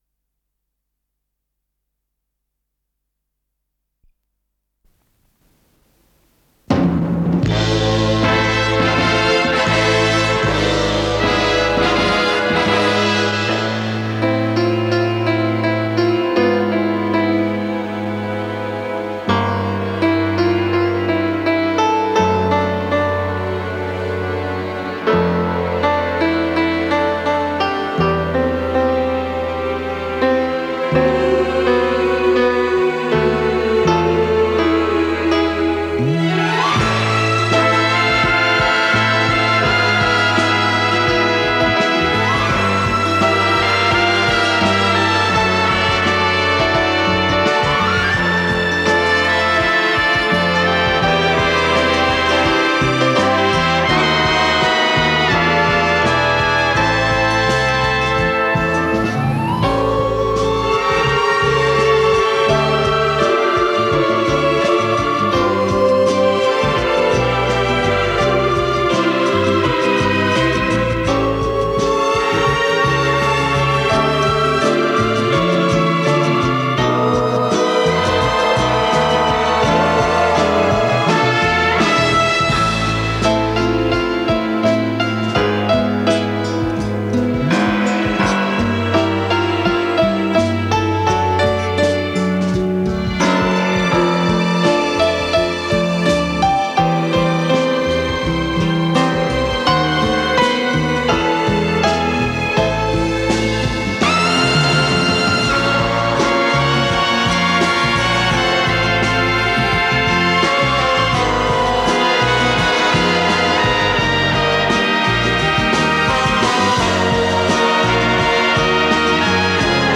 с профессиональной магнитной ленты
ПодзаголовокПьеса